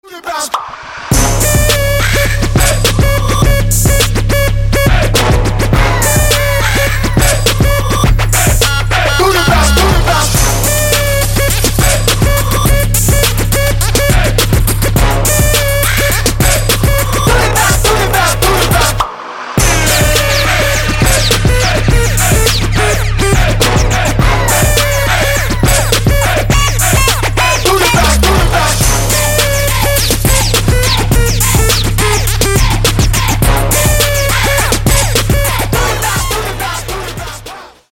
• Качество: 224, Stereo
Trap
трэп